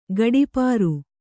♪ gaḍi pāru